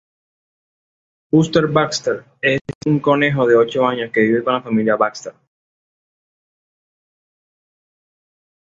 co‧ne‧jo
/koˈnexo/